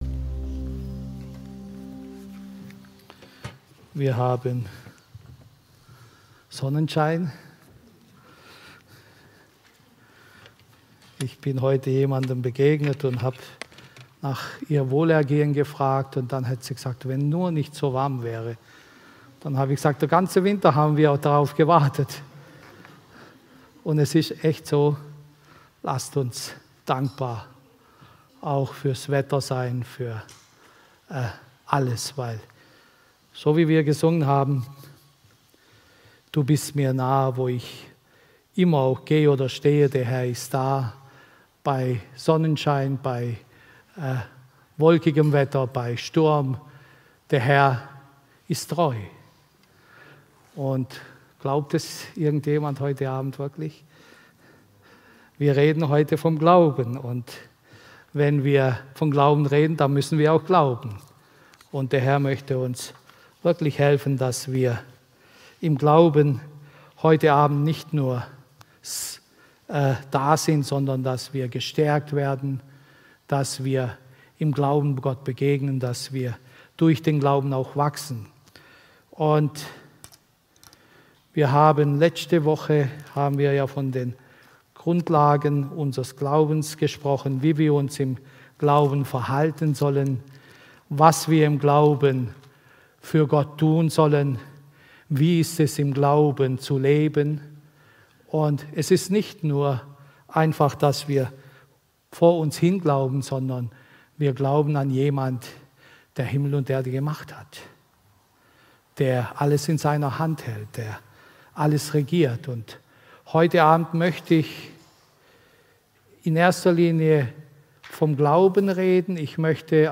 Predigt
im Christlichen Zentrum Villingen-Schwenningen